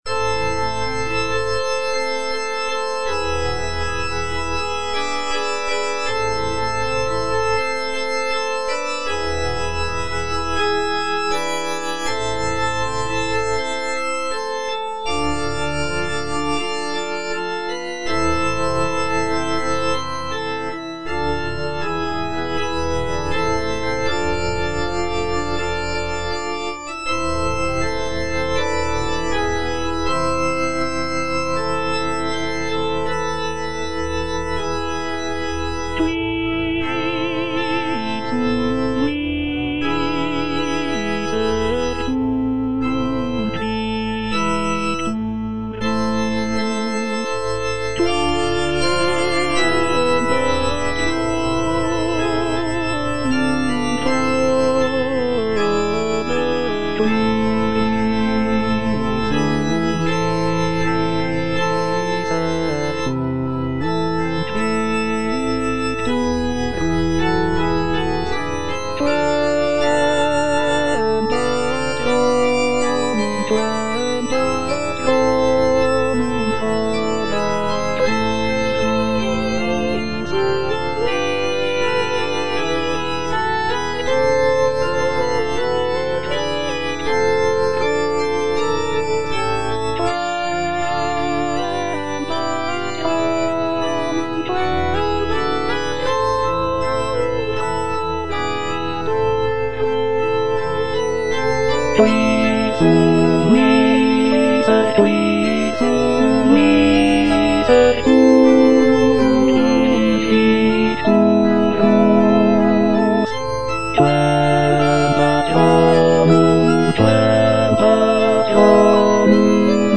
Bass (Emphasised voice and other voices) Ads stop
is a sacred choral work rooted in his Christian faith.